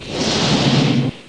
1 channel
00429_Sound_GeisterBahnAus.mp3